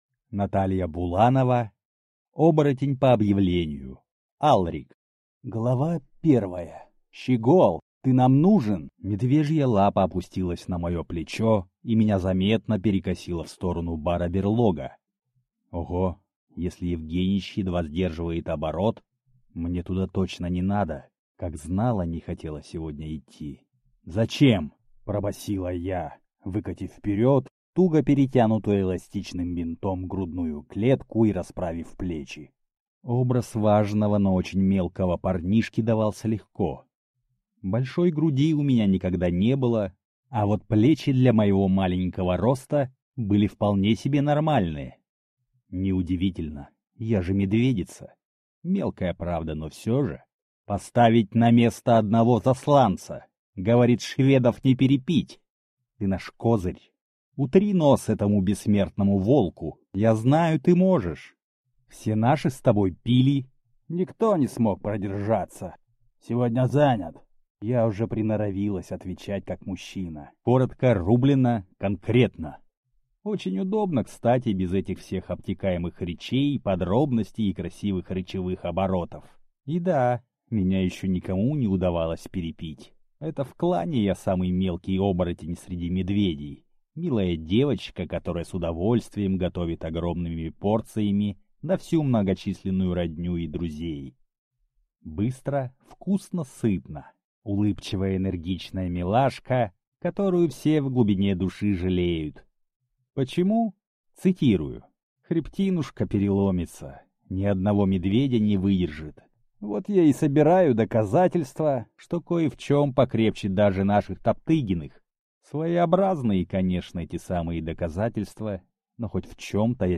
Аудиокнига Оборотень по объявлению. Алрик | Библиотека аудиокниг